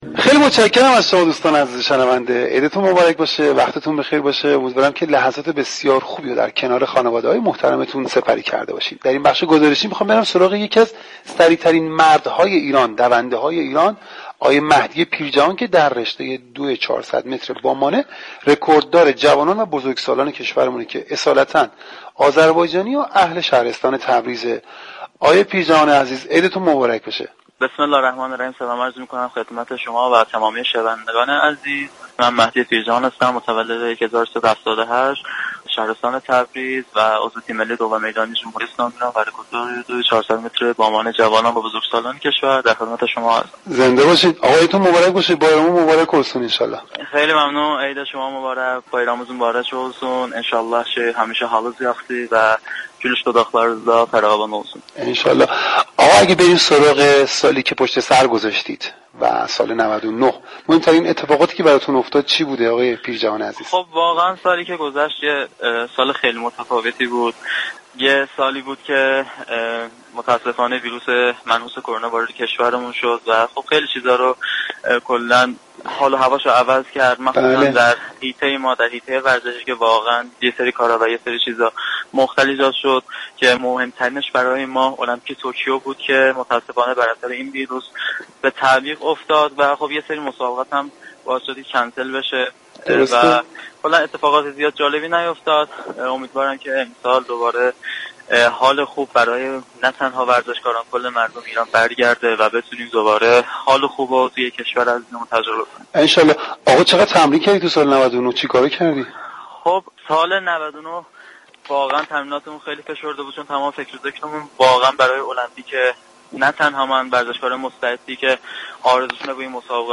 گفتگو رادیو ورزش با یكی از سریعترین دونده های جهان
شما می توانید از طریق فایل صوتی پیوست شنونده این مصاحبه باشید. "ورزش در سالی كه گذشت" به بررسی رخدادهای مهم ورزش سال 1399 و مصاحبه با ورزشكاران می پردازد.